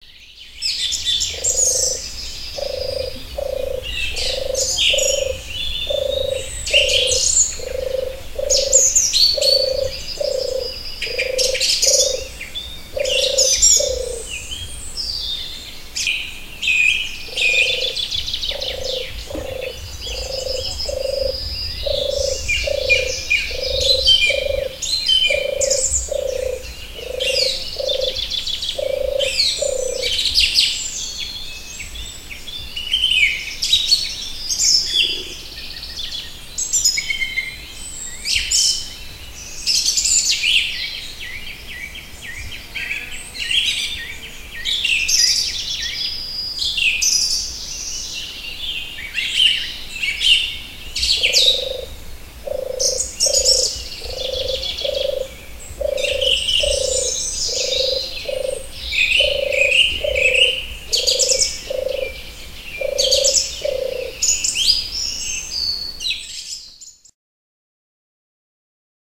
Turturică (Streptopelia turtur)